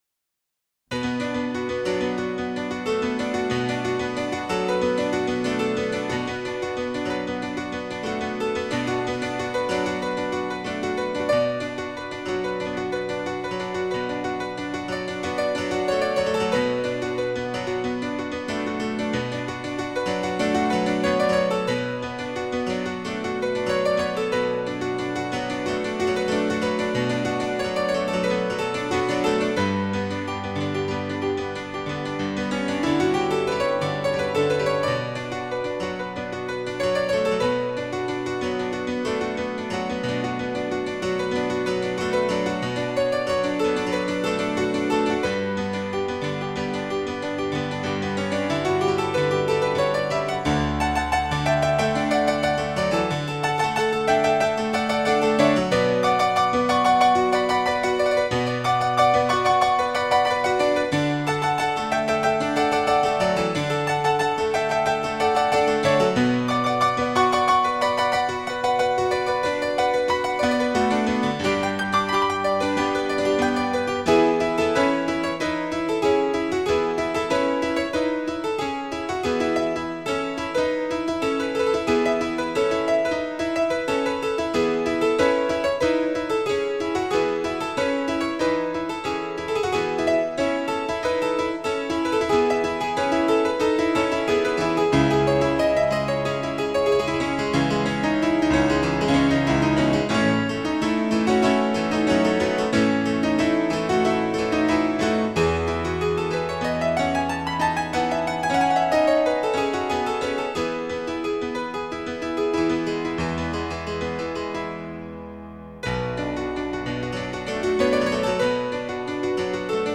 僅低音質壓縮 , 供此線上試聽
充滿寧靜 喜悅 生命力